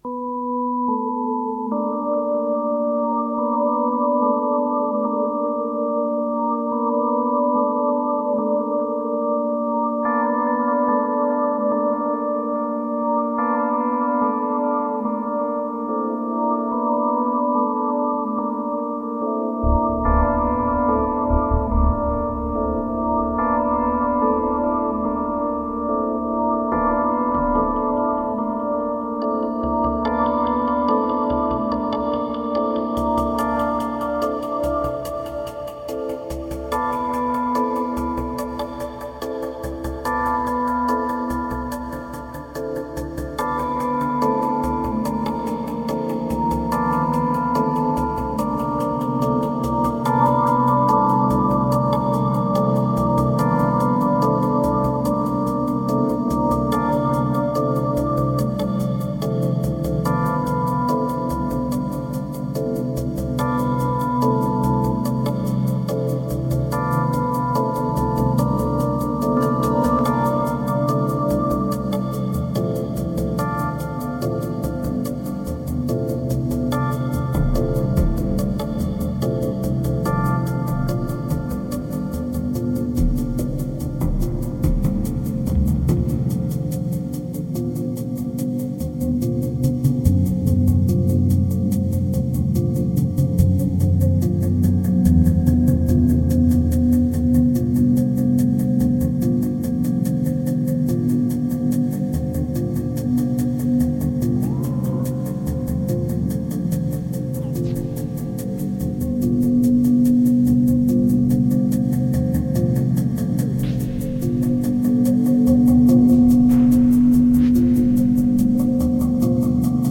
1916📈 - 56%🤔 - 72BPM🔊 - 2012-08-18📅 - 63🌟